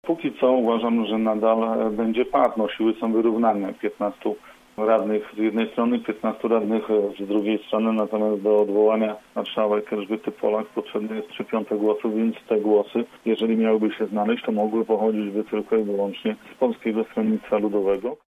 Dziś w „Rozmowie po 9” temat komentował Jacek Budziński, zielonogórski radny PiS: